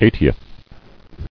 [eight·i·eth]